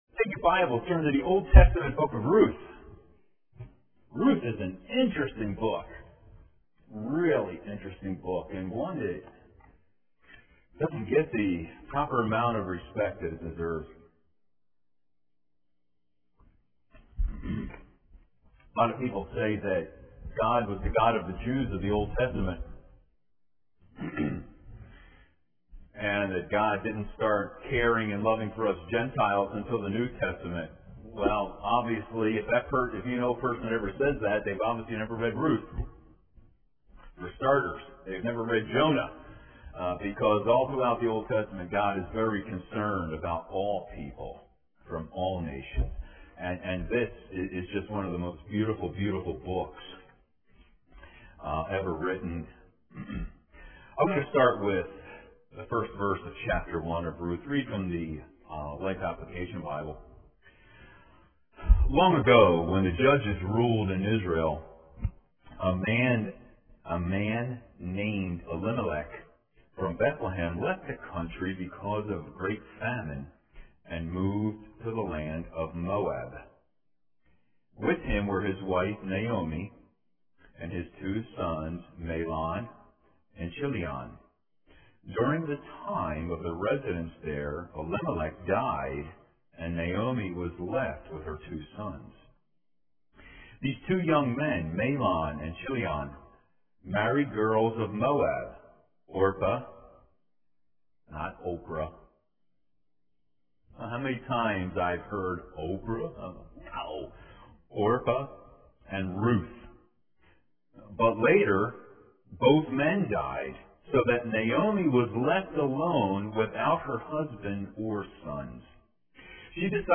Click the “Play” button below to begin listening to the recording (from the 9:30 service), then scroll down and follow along…